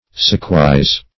suchwise - definition of suchwise - synonyms, pronunciation, spelling from Free Dictionary Search Result for " suchwise" : The Collaborative International Dictionary of English v.0.48: Suchwise \Such"wise`\, adv. In a such a manner; so.